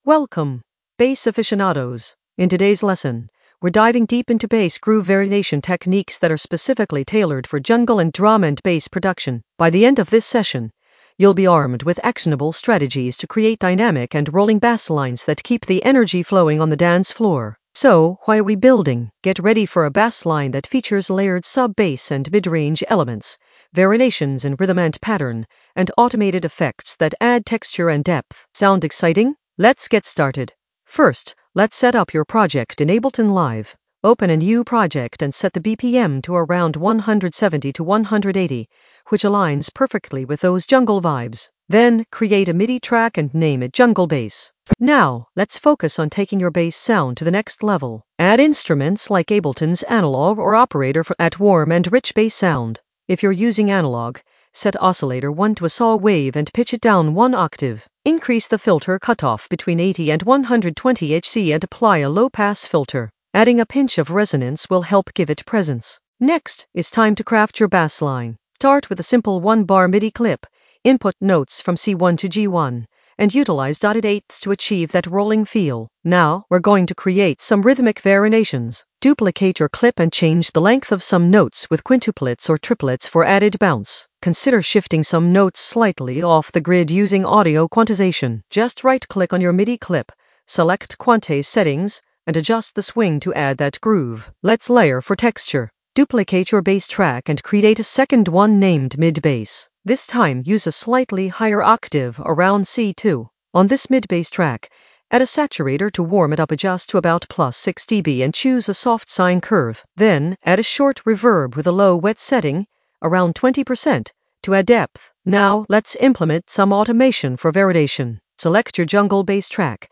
An AI-generated intermediate Ableton lesson focused on Bass groove variation techniques for jungle in the Basslines area of drum and bass production.
Narrated lesson audio
The voice track includes the tutorial plus extra teacher commentary.